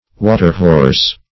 Waterhorse \Wa"ter*horse`\, n. A pile of salted fish heaped up to drain.